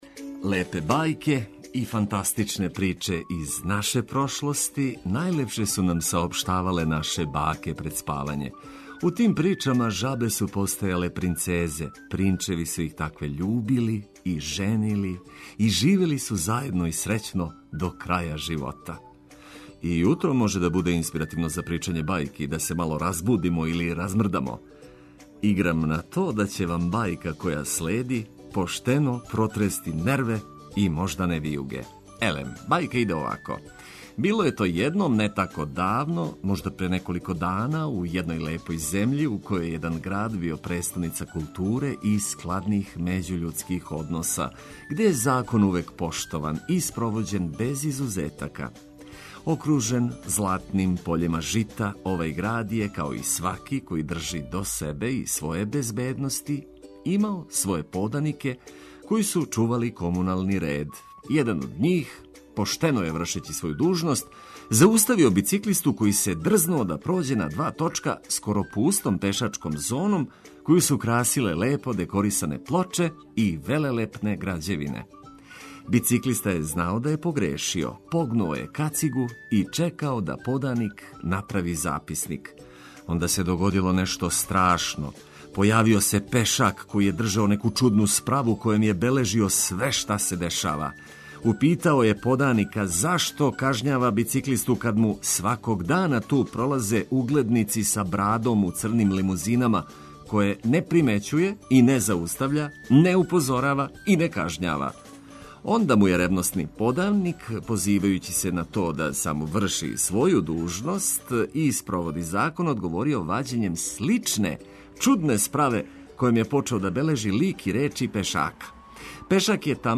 Блиставо ведра музика и само важне, кратке вести.